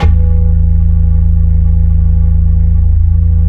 PERC FLUTE-L.wav